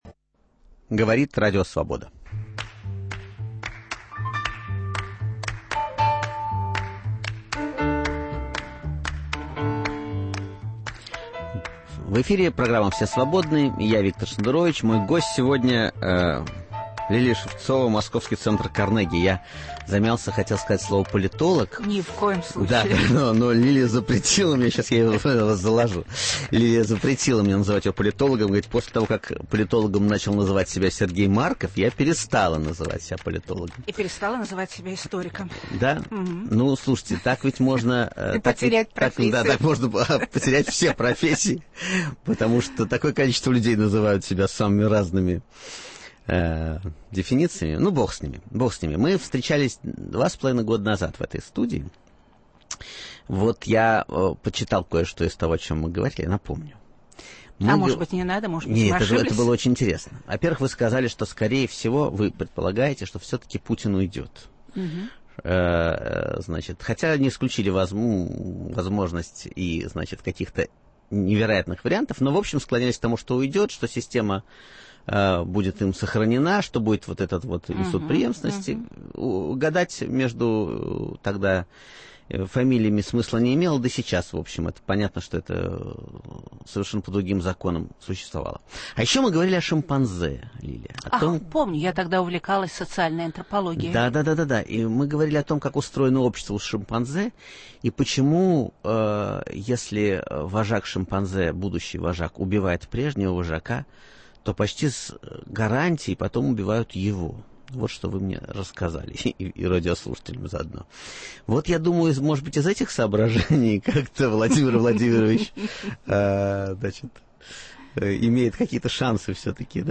В гостях у Виктора Шендеровича - эксперт Центра Карнеги Лилия Шевцова.